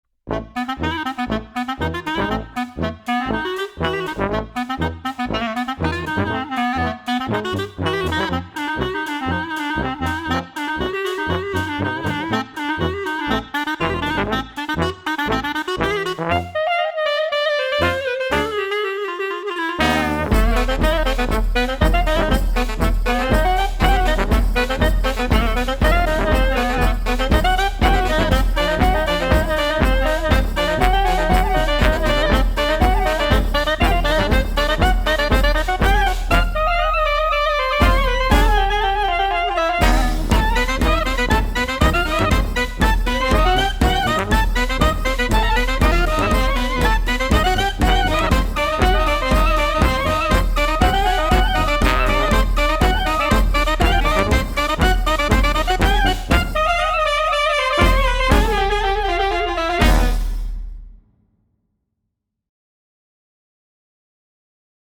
BALKAN CLARINET是一款新的可演奏的Bb单簧管，捕捉了巴尔干音乐及其他音乐的风格和细微差别，非常适合许多音乐流派，从爵士乐，流行音乐，传统音乐，布雷戈维奇式的乐谱和世界音乐。
巴尔干单簧管具有高度直观、易于使用且随时可用的界面，具有 2 种顶级混响算法、延迟和类似踏板的坚韧失真。
• 基于精心采样的单簧管，声音现代强劲多样化
• 提供了18种不同的演奏技法，可以快速适应不同的音乐风格，如爵士、流行、传统音乐、Bregović式的配乐和世界音乐。